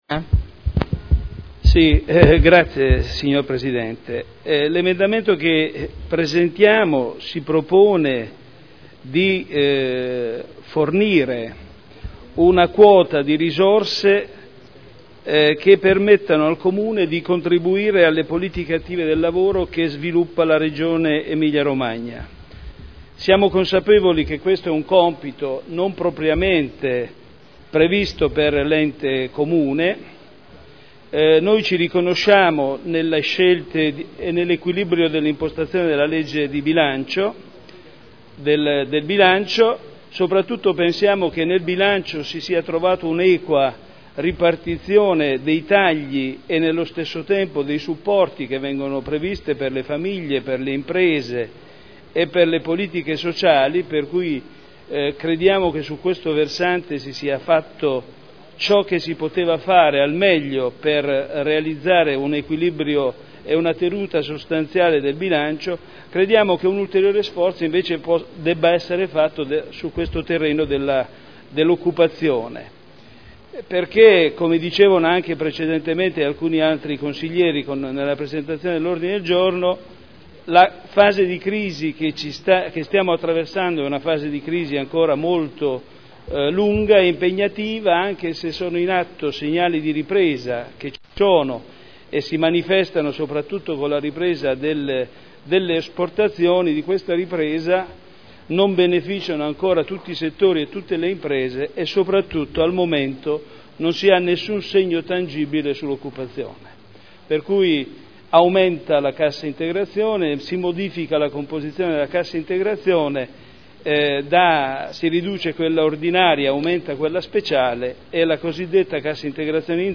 Seduta 28/03/2011. Presentazione di Emendamento n. 30106. Incentivare l'assunzione.
Audio Consiglio Comunale